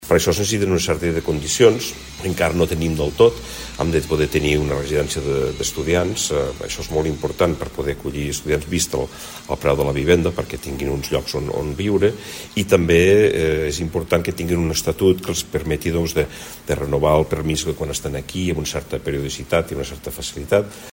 Minoves ha fet aquestes declaracions durant la roda de premsa posterior a la inauguració del Seminari CRUE-UdA, celebrat a Sant Julià de Lòria.